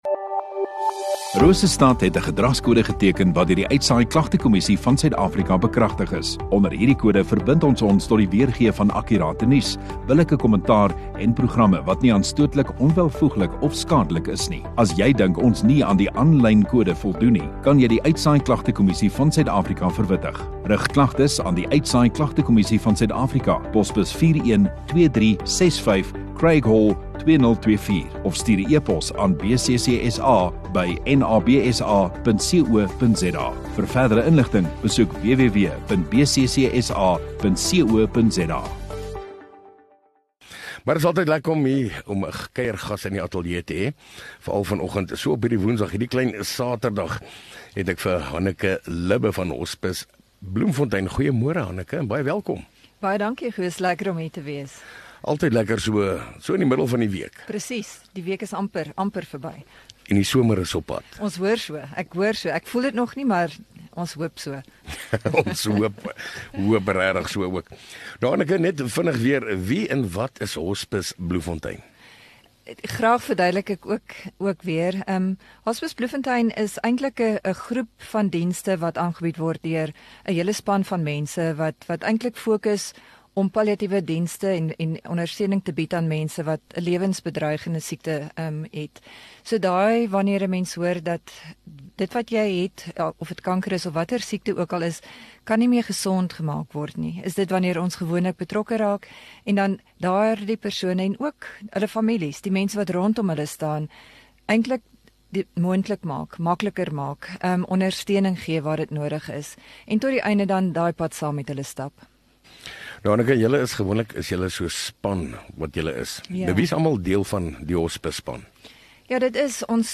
Radio Rosestad View Promo Continue Radio Rosestad Install Gemeenskap Onderhoude 7 Aug Hospice Bloemfontein